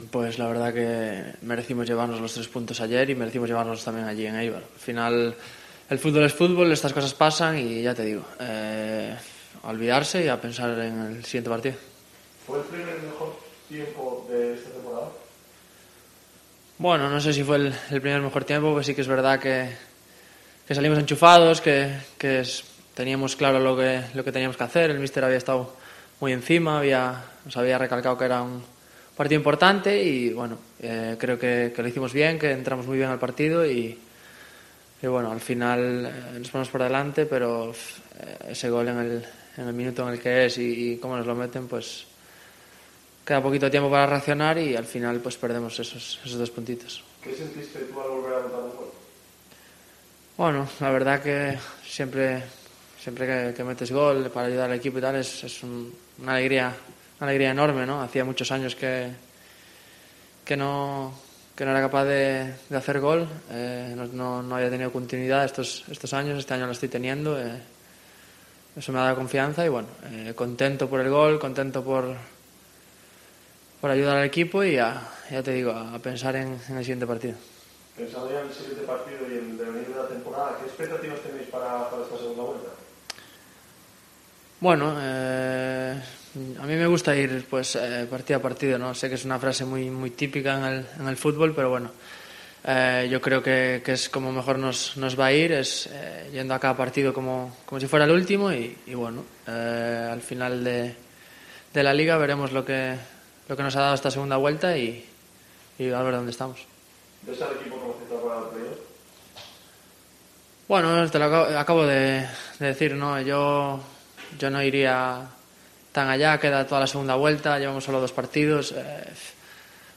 Rueda de prensa David Costas (post Eibar)